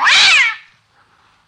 PixelPerfectionCE/assets/minecraft/sounds/mob/cat/hitt2.ogg at ca8d4aeecf25d6a4cc299228cb4a1ef6ff41196e